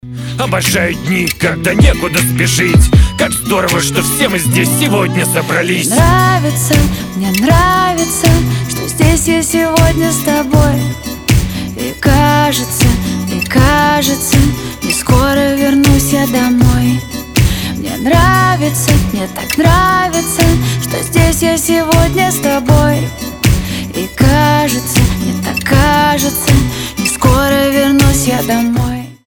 • Качество: 320, Stereo
гитара
женский вокал
красивый мужской голос